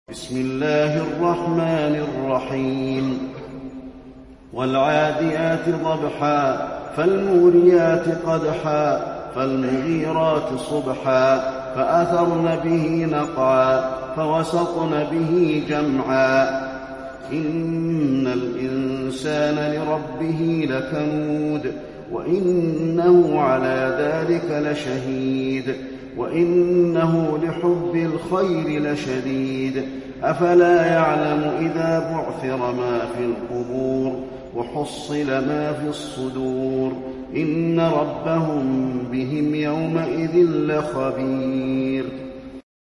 المكان: المسجد النبوي العاديات